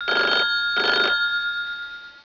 Sound Effects for Windows
phonerng.wav